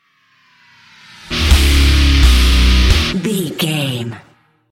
Aeolian/Minor
E♭
drums
electric guitar
bass guitar
Sports Rock
hard rock
aggressive
energetic
intense
nu metal
alternative metal